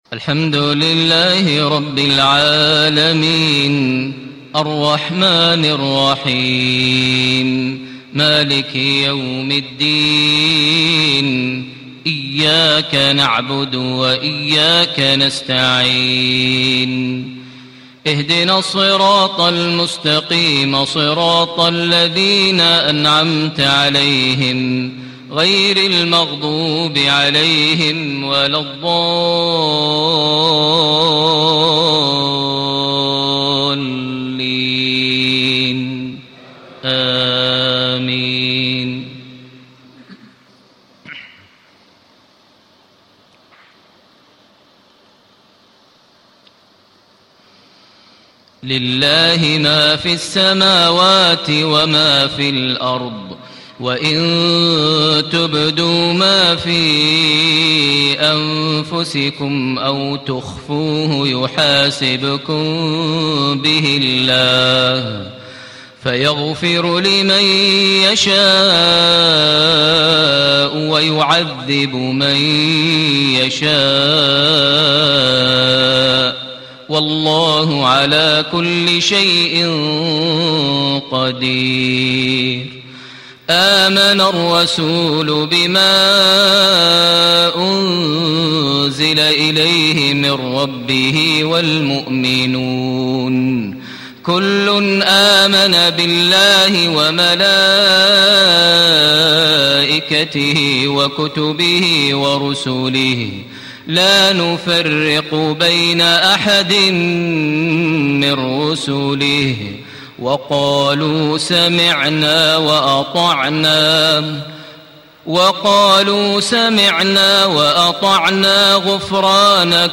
صلاة المغرب، الثلاثاء 1-8-1436 هـ لأواخر سورة البقرة > 1436 🕋 > الفروض - تلاوات الحرمين